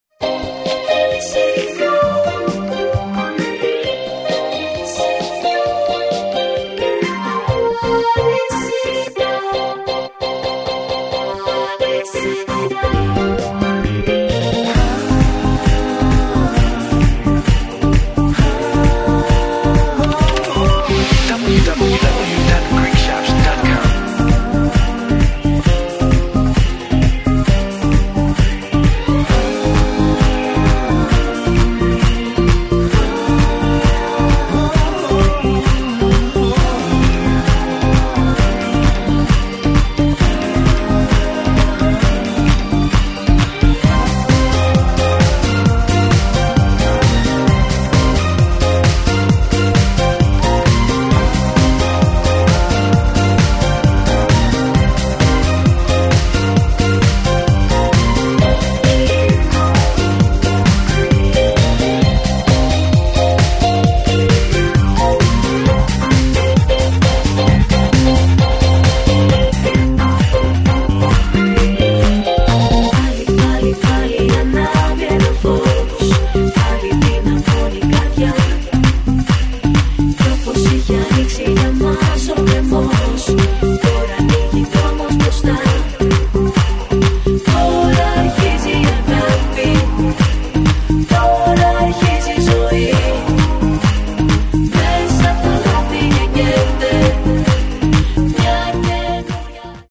Super chart hits remixed for great dance hits.